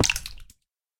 Minecraft Version Minecraft Version 25w18a Latest Release | Latest Snapshot 25w18a / assets / minecraft / sounds / mob / guardian / land_hit3.ogg Compare With Compare With Latest Release | Latest Snapshot
land_hit3.ogg